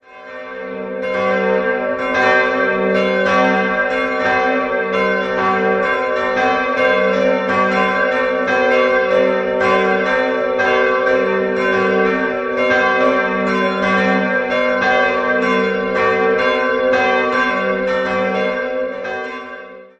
3-stimmiges TeDeum-Geläute: g'-b'-c'' Die große und die kleine Glocke wurde 1951 von der Firma Rincker in Sinn gegossen, die mittlere stammt von der Firma Geissendörfer und entstand 1856 in Nördlingen.